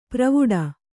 ♪ pravuḍa